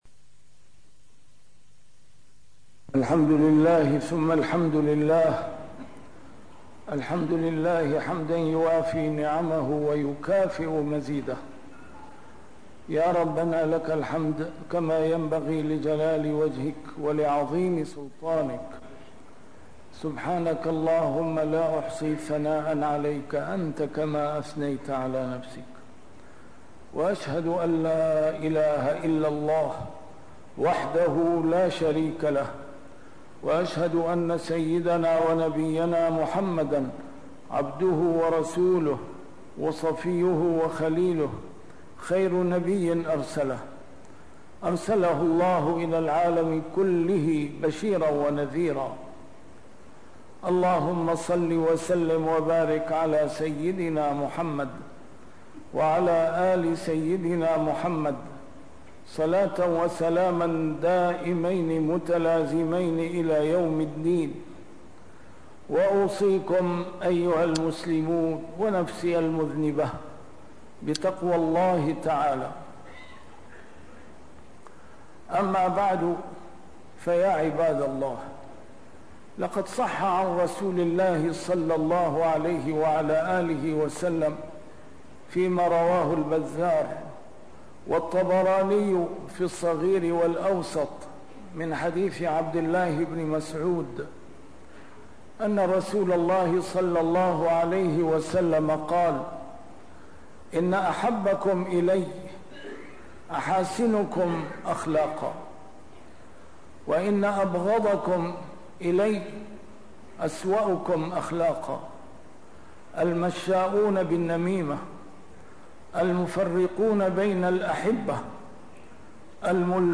A MARTYR SCHOLAR: IMAM MUHAMMAD SAEED RAMADAN AL-BOUTI - الخطب - حسن الخلق .. سلاحنا الأمضى